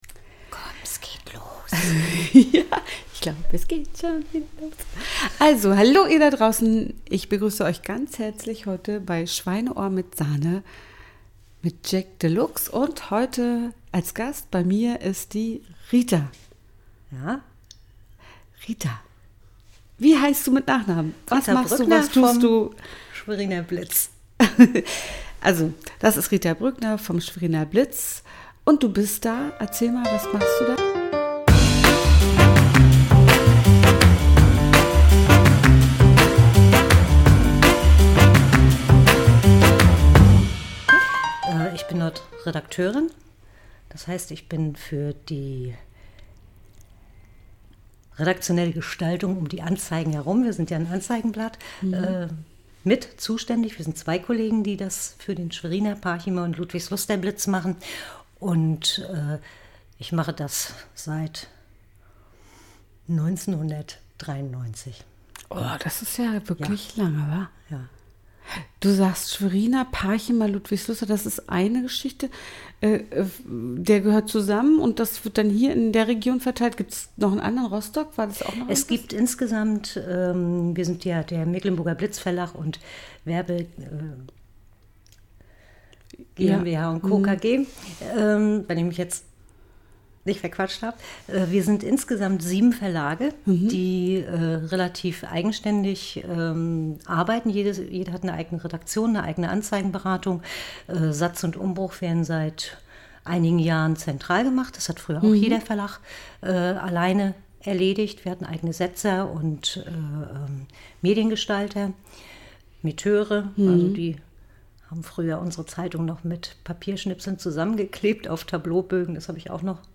Zwei Frauen, die beide direkt von den Corona Beschränkungen betroffen sind unterhalten sich über das „heute“ und ein hoffentlich besseres „morgen“.